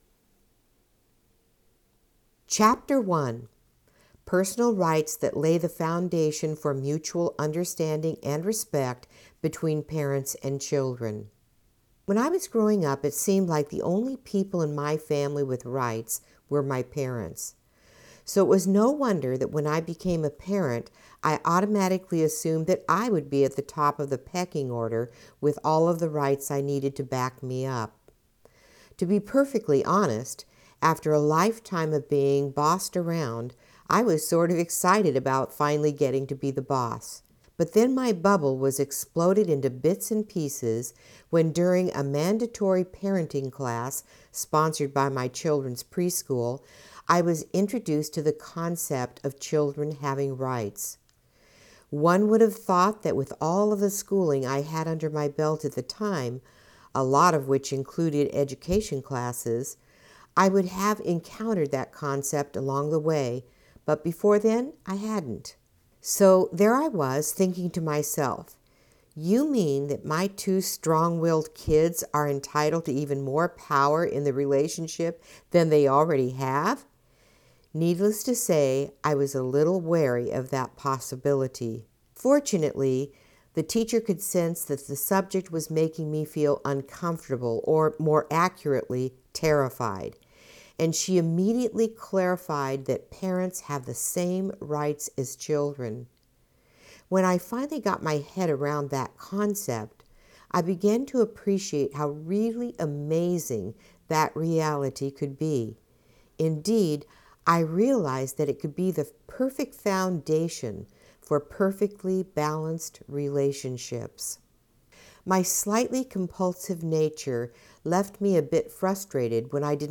AUDIO BOOK ABOUT PARENT/CHILD RIGHTS FOR PARENTS